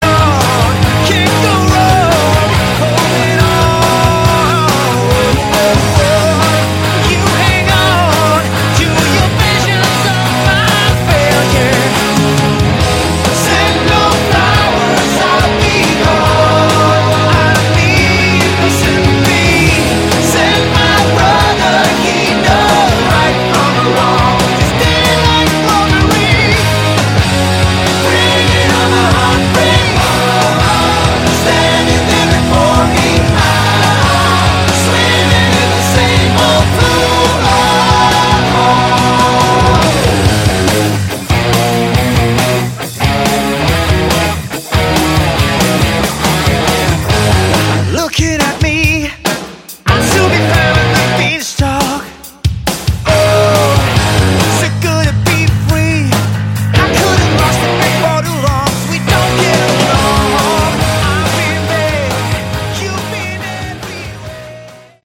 Category: Melodic Rock
guitar
lead vocals
bass, backing vocals
drums